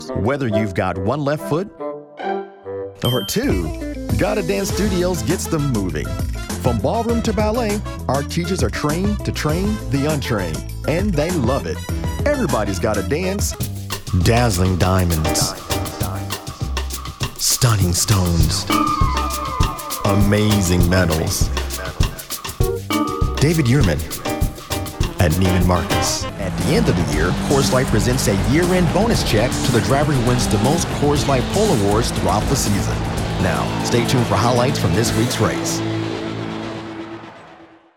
A sophisticated sound for Business and Broadcasting.
I have a versatile voice that is a natural choice for companies looking for a clear, concise, nice and easy sound.